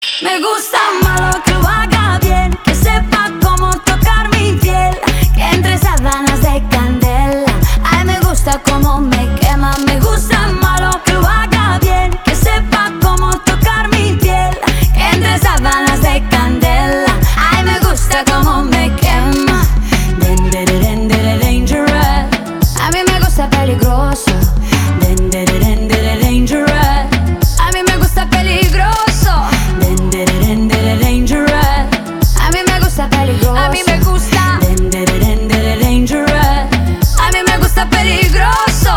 • Качество: 320, Stereo
поп
ритмичные
заводные
dancehall
латина